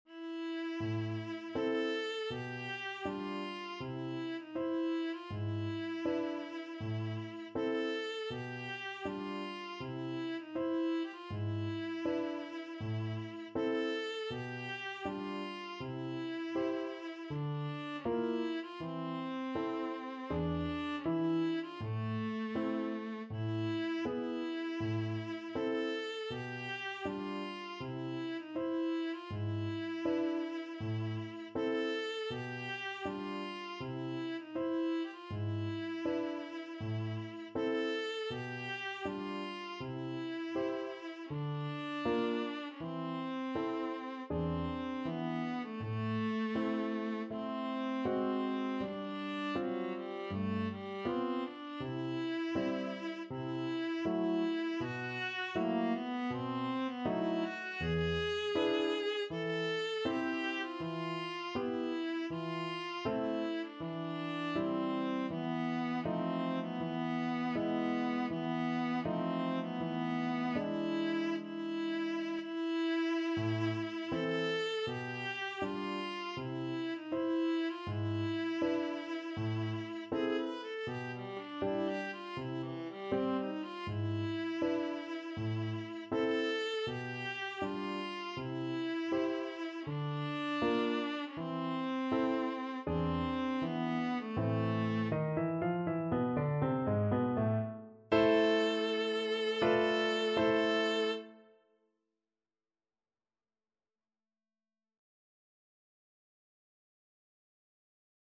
Viola
Andante = c.80
4/4 (View more 4/4 Music)
A minor (Sounding Pitch) (View more A minor Music for Viola )
Classical (View more Classical Viola Music)